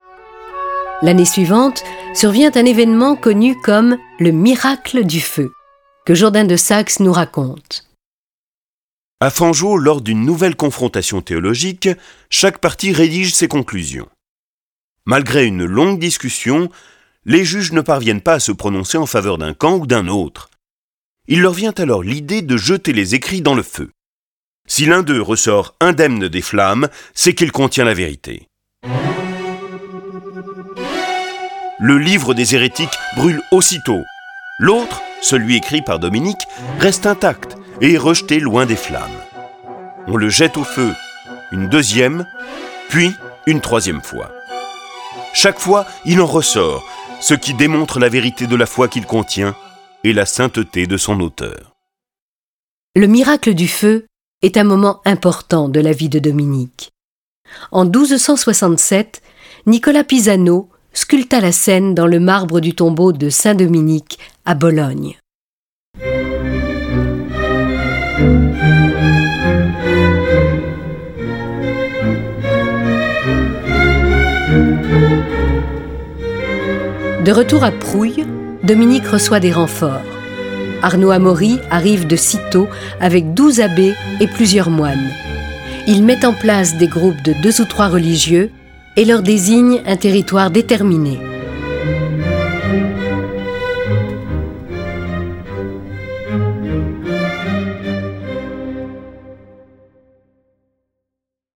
Cette version sonore de la vie de Dominique est animée par huit voix et accompagnée de plus de trente morceaux de musique classique.
Le récit et les dialogues sont illustrés avec les musiques de Bach, Beethoven, Bizet, Charpentier, Franck, Haendel, Mahler, Marcello, Pachelbel, Pergolèse, Schumann, Telemann, Vivaldi, Wagner.